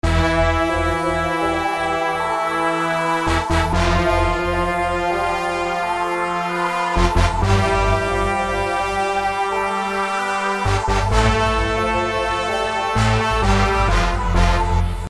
黄铜复出
描述：铜管和低音的建立
Tag: 130 bpm Hip Hop Loops Brass Loops 2.53 MB wav Key : Unknown